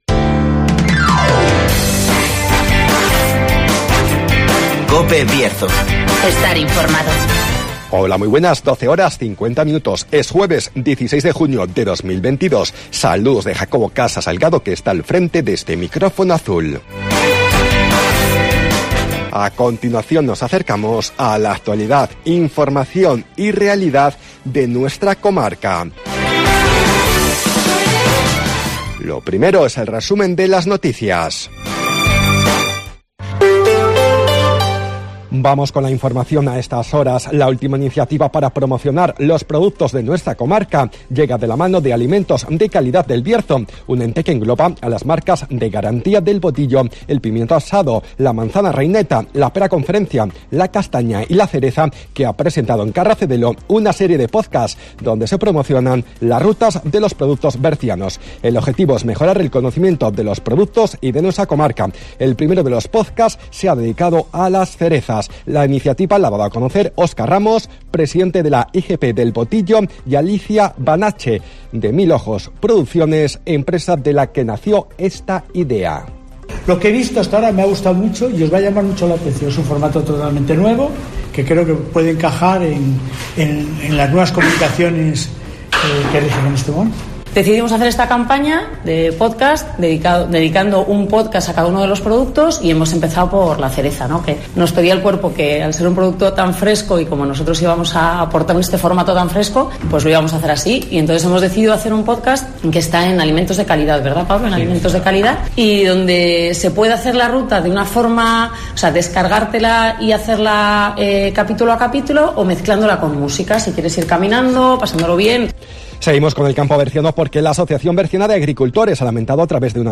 AUDIO: Resumen de las noticias, El Tiempo y Agenda